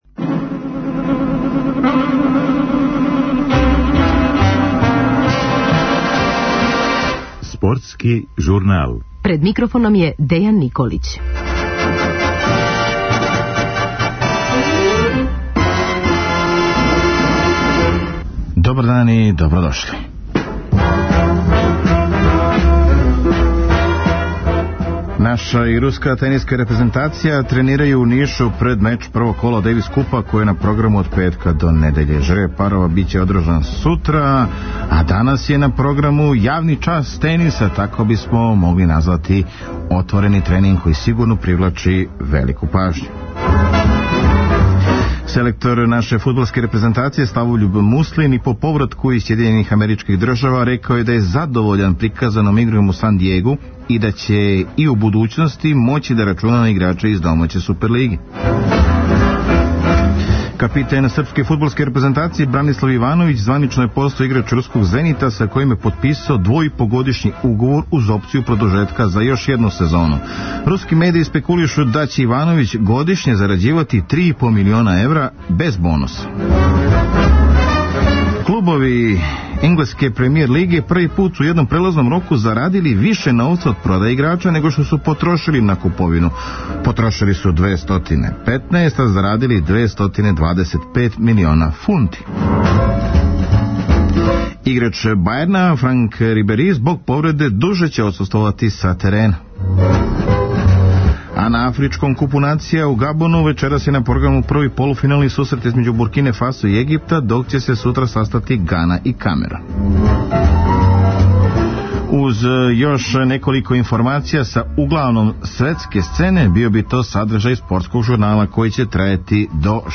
У данашњој емисији чућете разговор са селектором фудбалске репрезентације Славољубом Муслином, после утакмице у Америци.